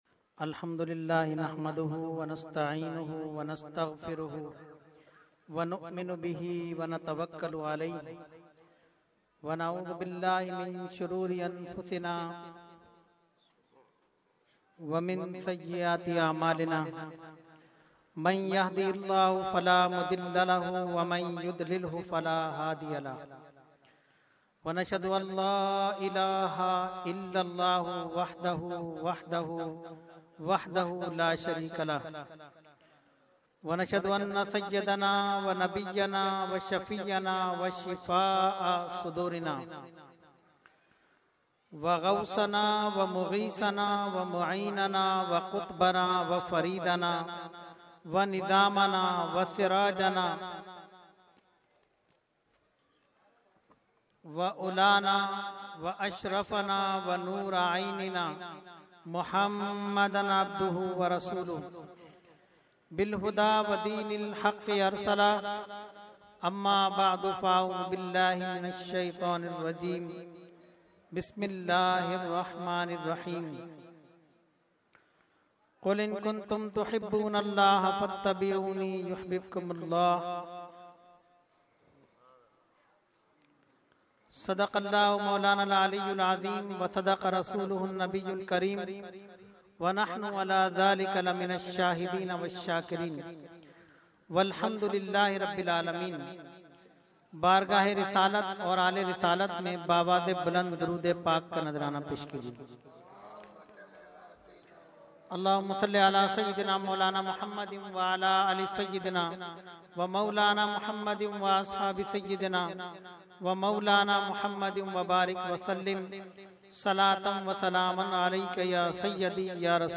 Category : Speech | Language : UrduEvent : Eid Milad Peetal Gali Gulbahar 2013